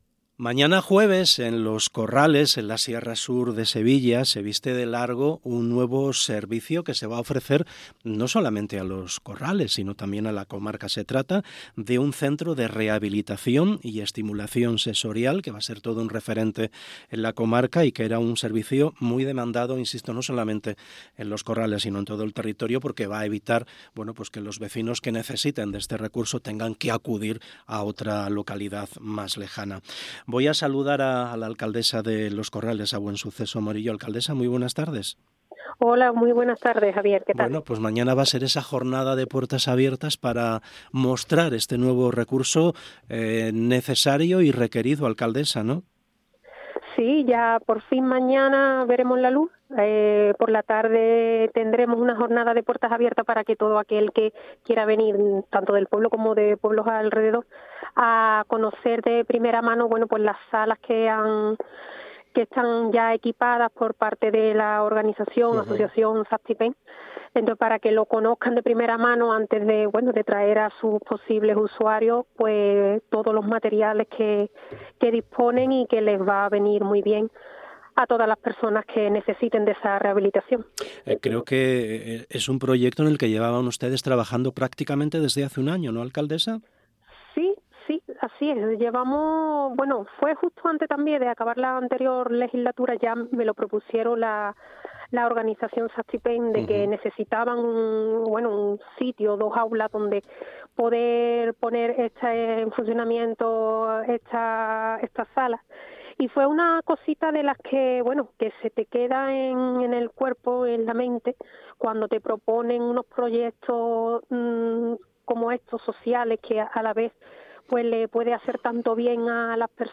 Entrevista Buensuceso Morillo, alcaldesa Los Corrales
Buensuceso Morillo, alcaldesa de Los Corrales ha pasado por el programa Hoy por Hoy SER Andalucía Centro y se ha mostrado muy satisfecha por haber finalizado este proyecto.